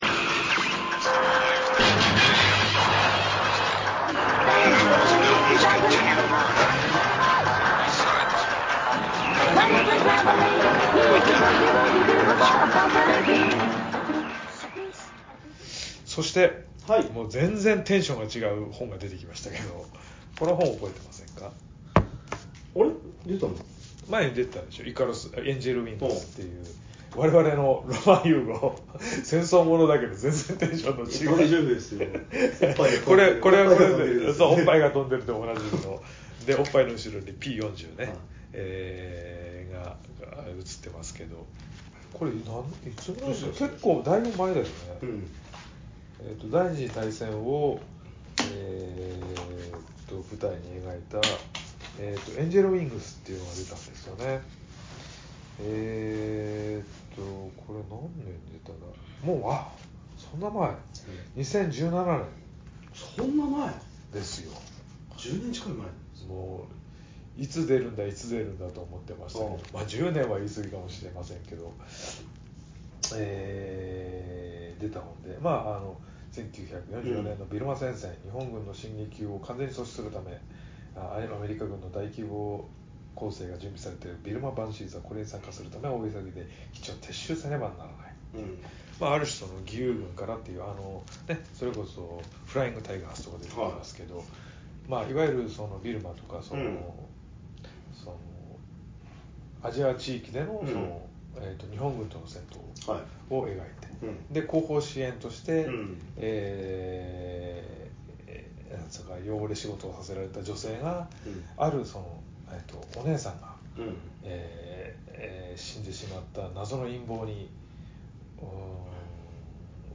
アメコミやバンドデシネがちょっと好きで、ちょっとアレな二人の男子が、至高の女子会を目指すエンタテインメントネットラジオです。